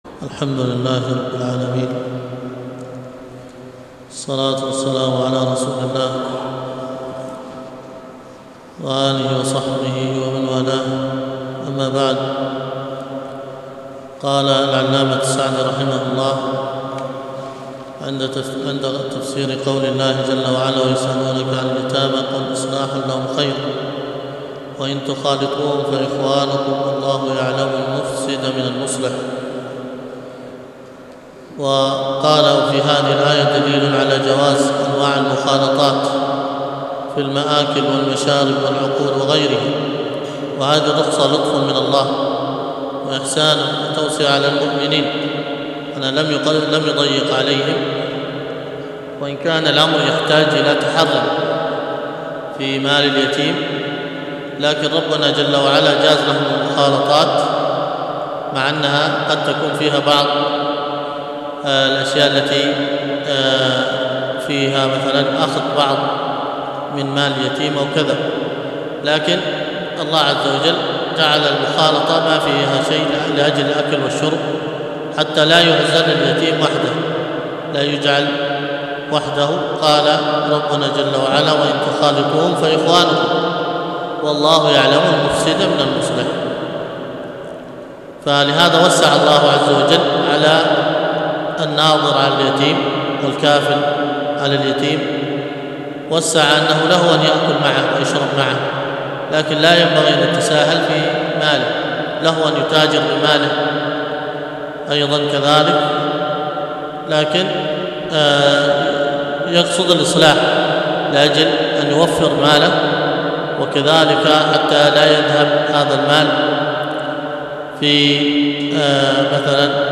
الدروس القرآن الكريم وعلومه